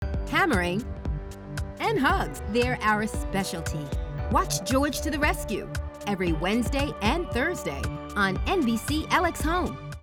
Female
English (North American)
Yng Adult (18-29), Adult (30-50)
Television Spots
Promo Announcer For Tv Show
0626G2R_NBC_LXHome_promo_video.mp3